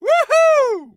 Звук счастливого мужчины уху